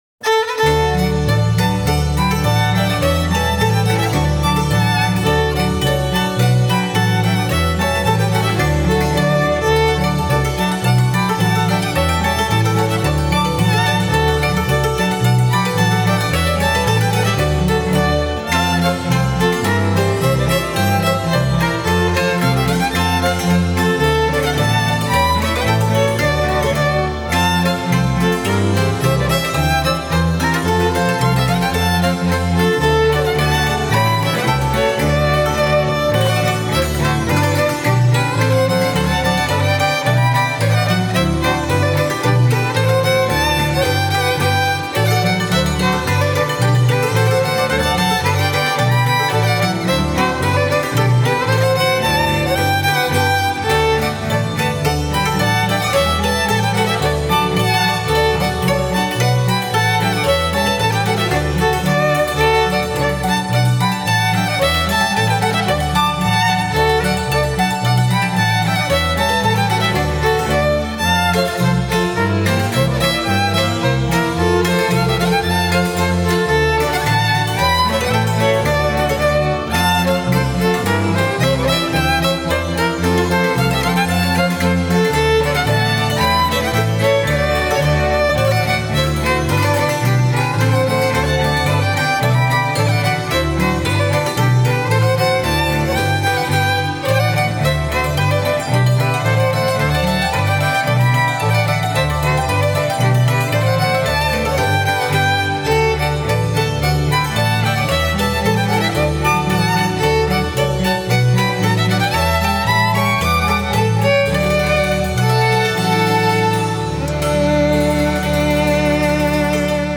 Original Appenzeller Streichmusik Alder (Urnäsch): s’Vaters Art (Schottisch)
Original Appenzeller Streichmusik Alder (Urnäsch)